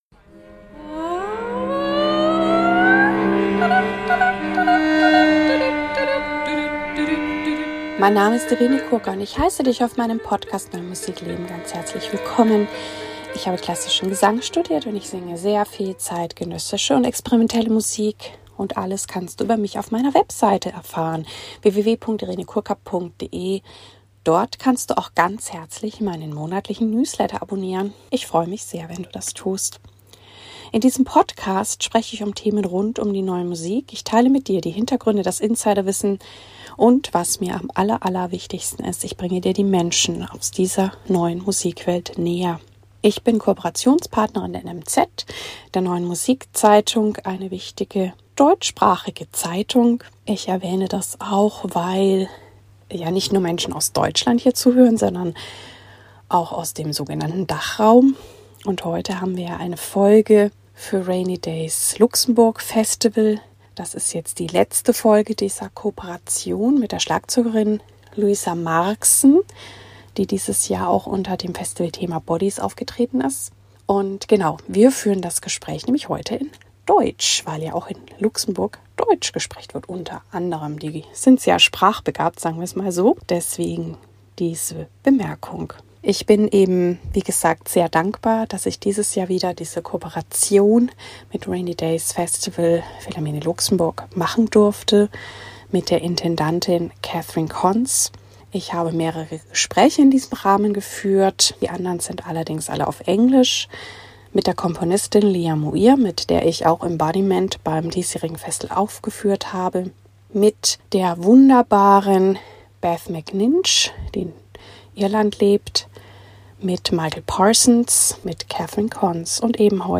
276 - Interview